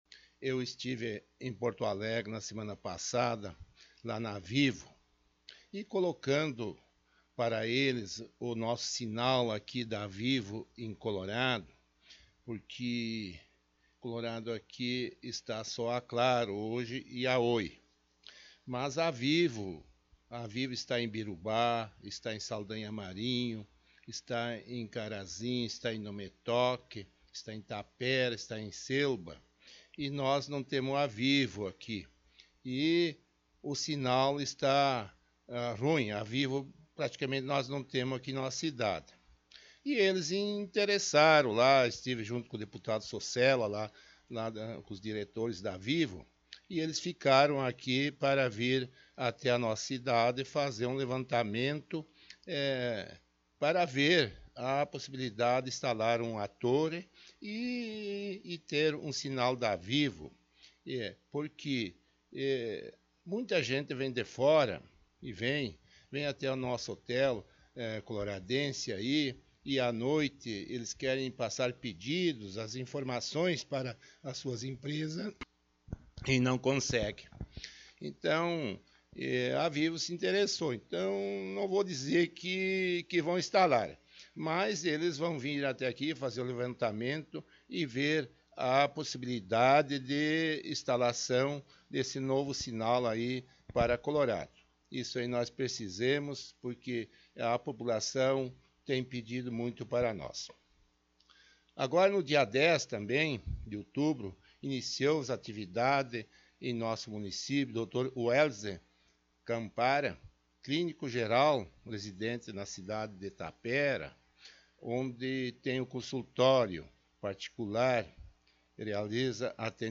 Em entrevista concedida ao Programa Presença de Colorado da Rádio Ibirubá, o prefeito municipal esteve falando sobre trabalhos, projetos e preocupações da Administração Municipal.